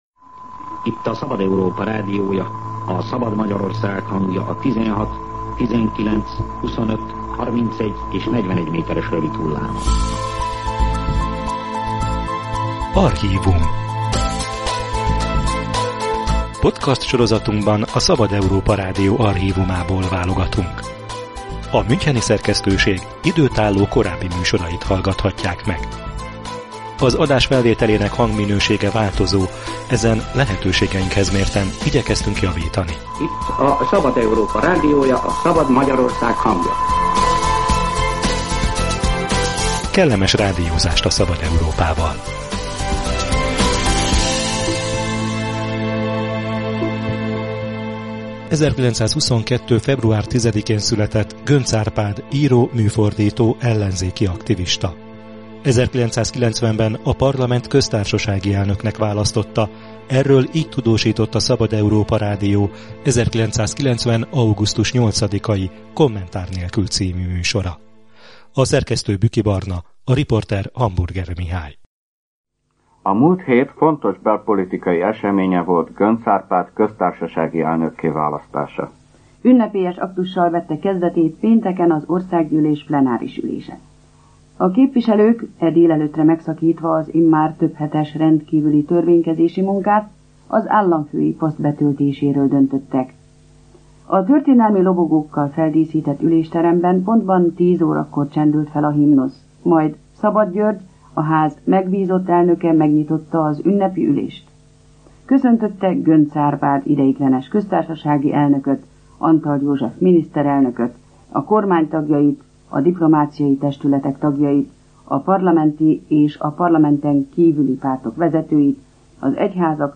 Tíz éve, 2015. október 6-án halt meg Göncz Árpád író, műfordító, ellenzéki aktivista. 1990-ben a parlament köztársasági elnöknek választotta, amelyről tudósított a Szabad Európa Rádió is. Az 1990. augusztus 8-án sugárzott, Kommentár nélkül című műsor részletével emlékezünk Göncz Árpádra.